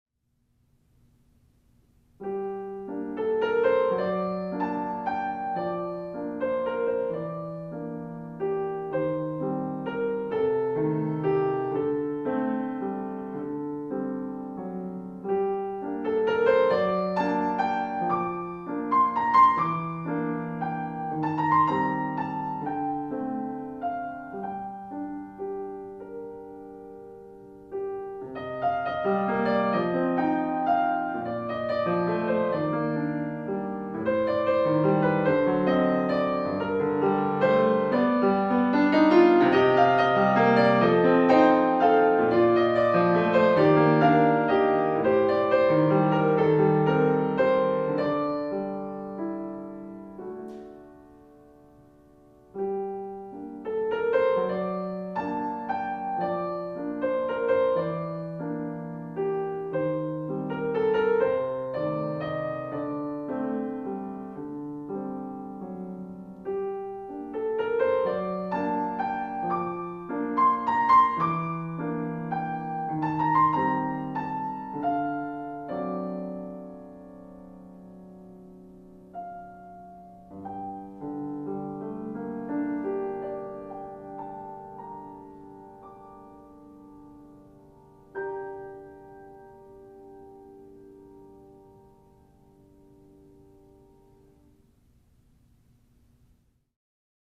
Posłuchajcie nagarnia tego pięknego, nastrojowego utworu.